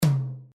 tom1.wav